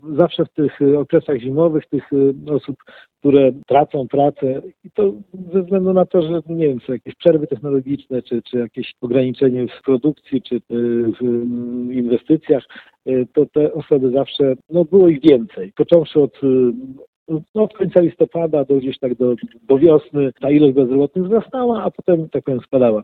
Sytuacja jest podobna do tej z ubiegłego roku – mówi Marek Chojnowski, starosta powiatu ełckiego.